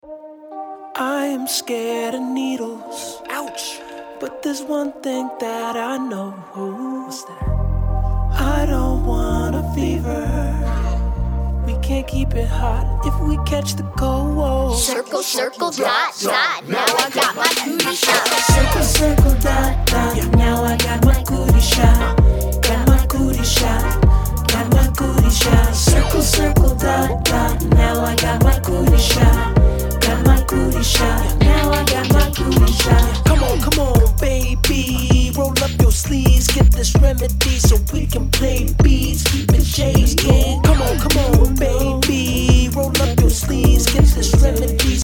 Miami-bass dance song